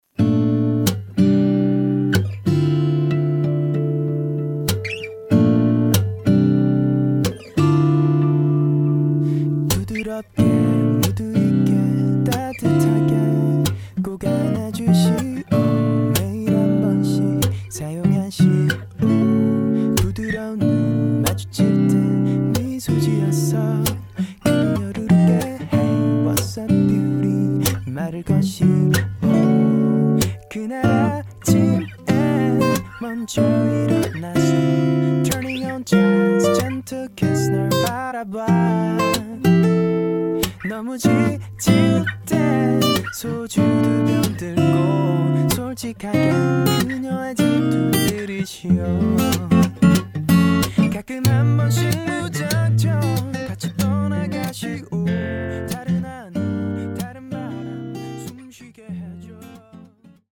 음정 원키 3:02
장르 가요 구분 Voice Cut